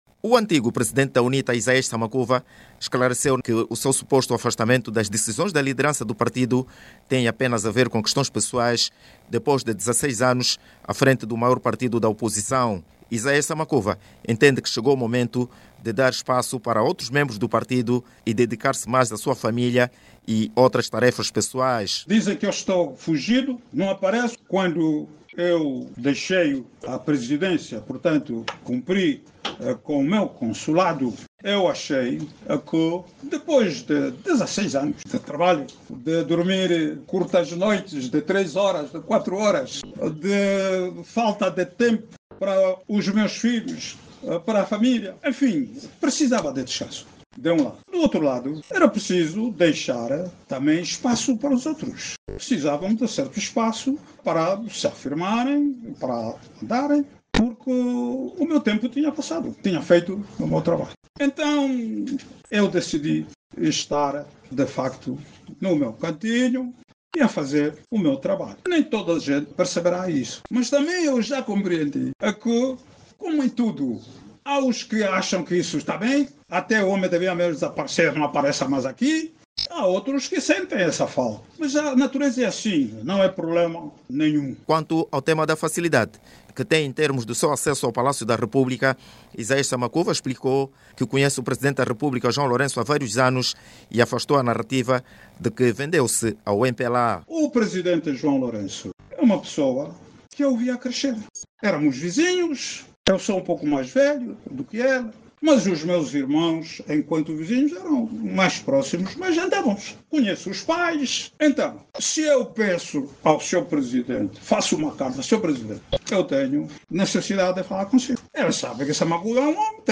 Isaías Samakuva, ex-Presidente da UNITA, esclarece que o seu afastamento das actividades do Partido foi uma decisão pessoal e familiar. Isaías Samakuva, que falava em Luanda durante uma conferência de imprensa, disse também que a decisão de deixar a liderança do partido serviu para dar oportunidade a outros militantes. Durante a conferência de imprensa Isaías Samakuva, falou da boa relação que tem com o Presidente João Loureço.